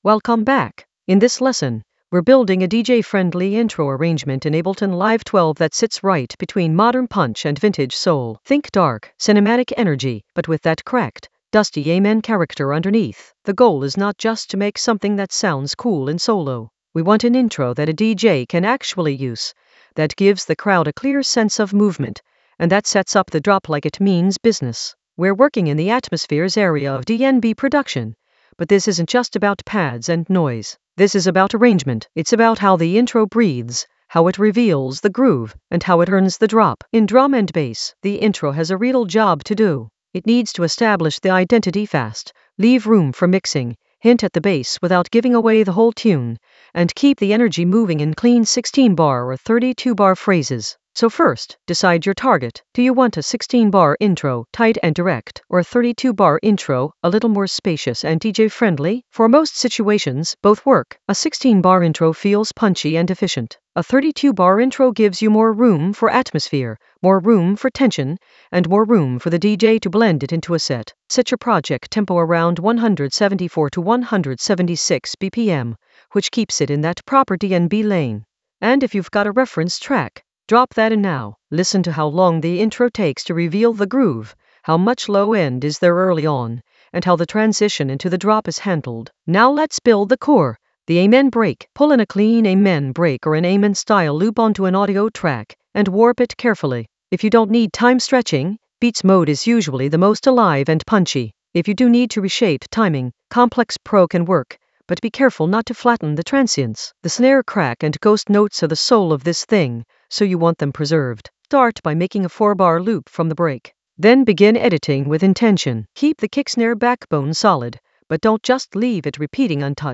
Narrated lesson audio
The voice track includes the tutorial plus extra teacher commentary.
An AI-generated intermediate Ableton lesson focused on Amen Science: DJ intro arrange with modern punch and vintage soul in Ableton Live 12 in the Atmospheres area of drum and bass production.